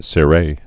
(sĭ-rā)